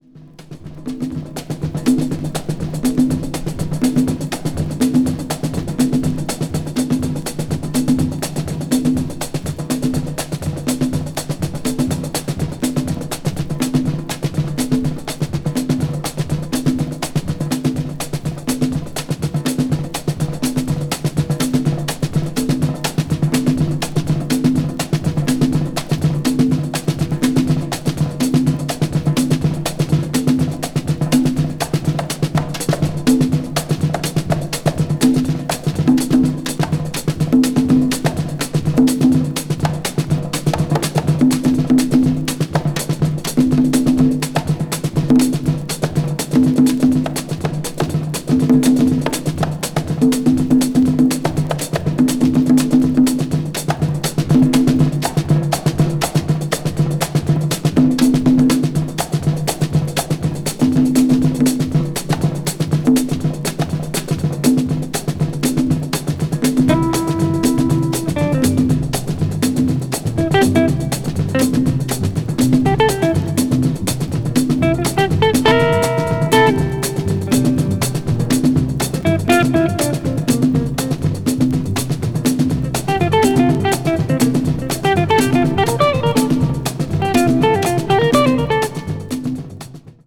Mono. coated gatefold sleeve.